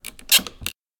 Other Sound Effects